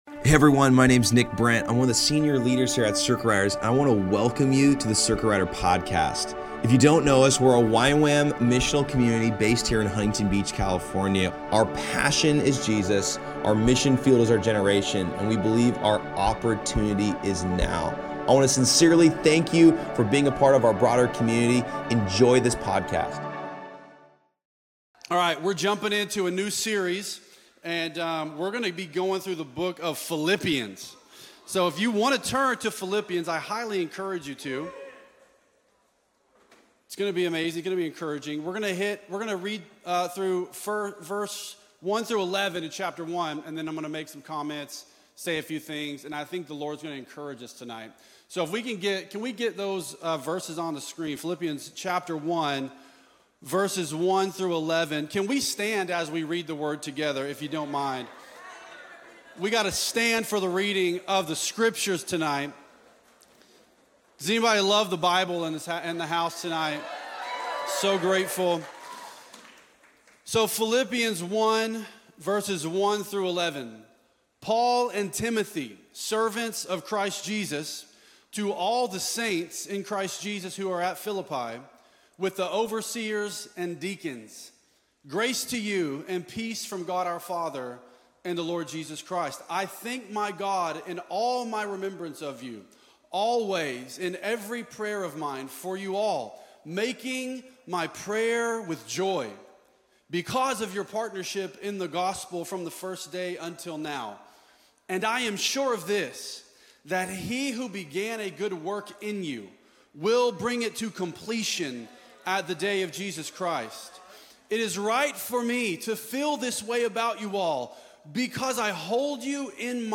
Details Series Monday Nights Passage Philippians 1:1-11 Location Costa Mesa Topics Philippians